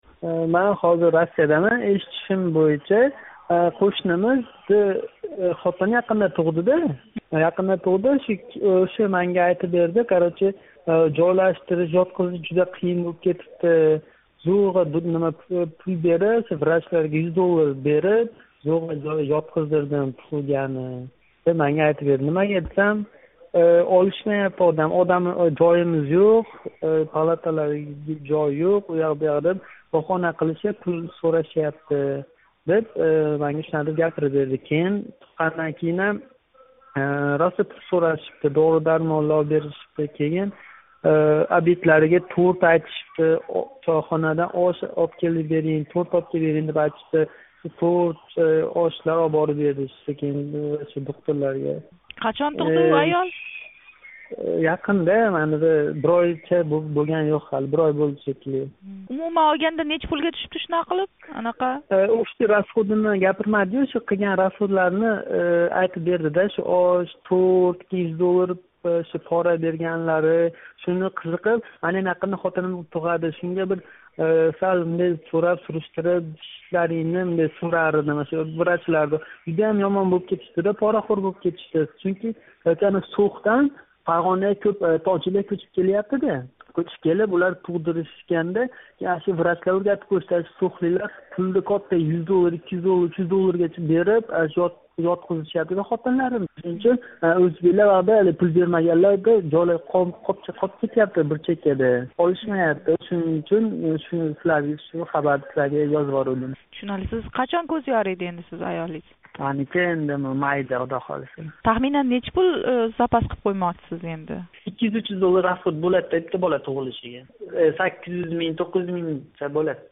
суҳбат